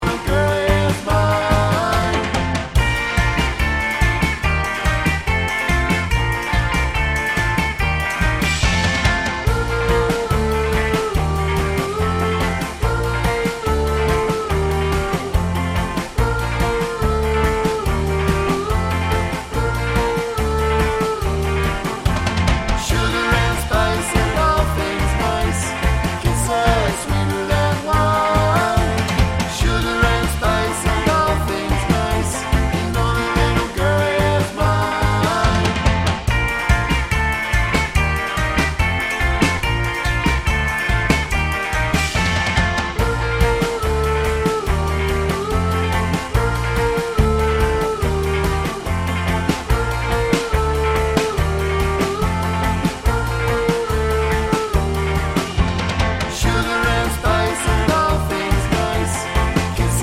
2025 Remake Two Semitones Down Pop (1960s) 2:15 Buy £1.50